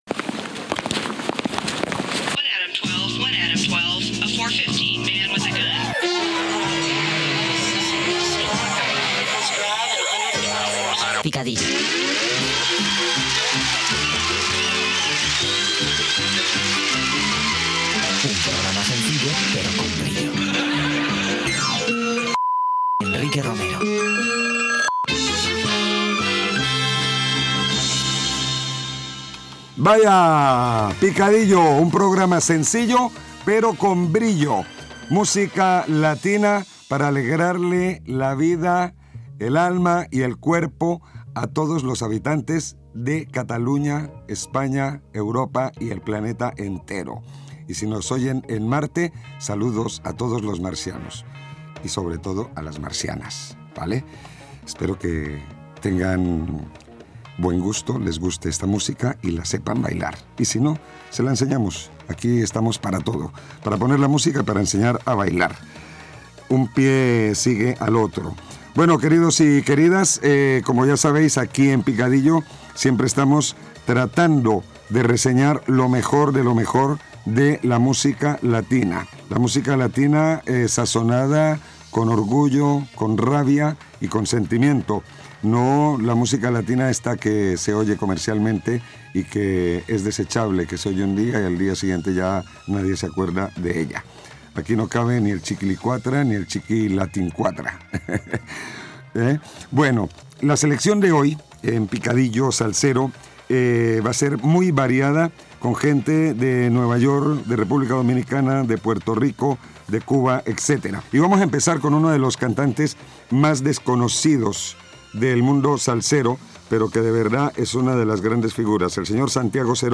Inici del programa de música latinoamericana, produït per Ràdio Ciutat de Badalona. Careta del programa, presentació, intenció i contingut del programa, selecció musical d'aquell dia i tema musical
Musical